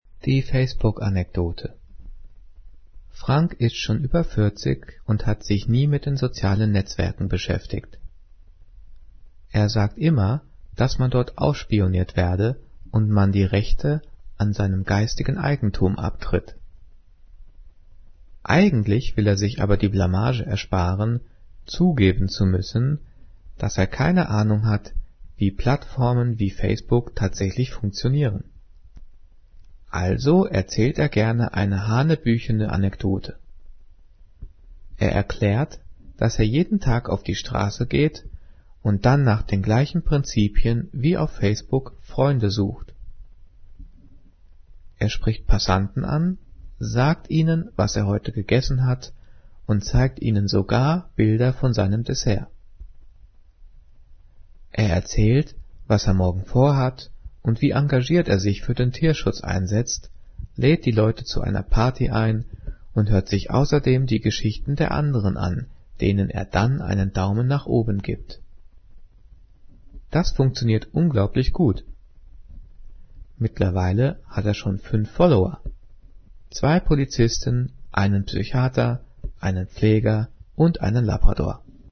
Gelesen:
gelesen-die-facebook-anekdote.mp3